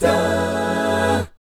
1-F#MI7 AA.wav